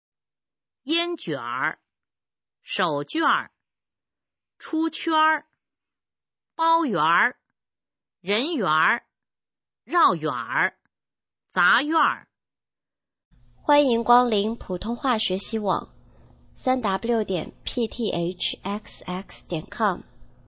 普通话水平测试用儿化词语表示范读音第7部分　/ 佚名